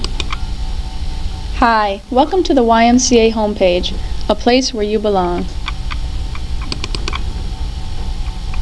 welcome mewssage in ".wav" format